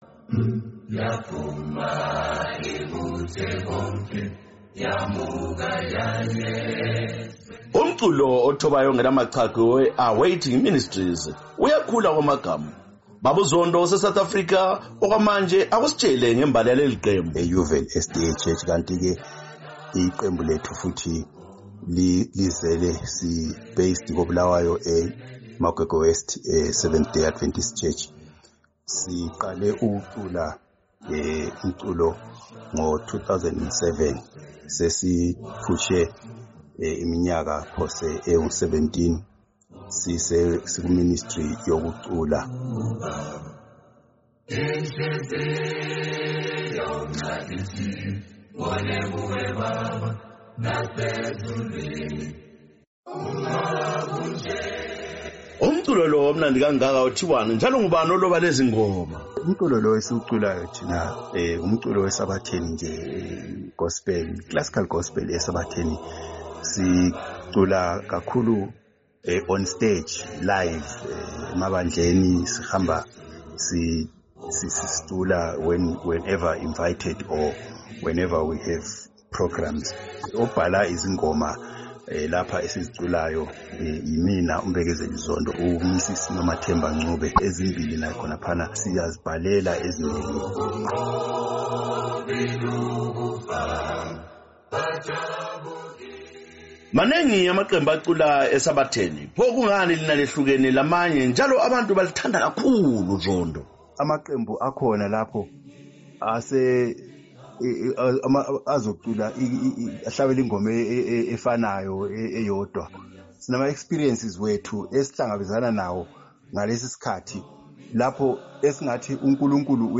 Woza Friday: Kuliviki sileqembu elicula amaphimbo amnandi eleAwaiting Ministries eliphansi kwebandla lezokholo lweSiKhristu eleSeventh Day Adventist Church.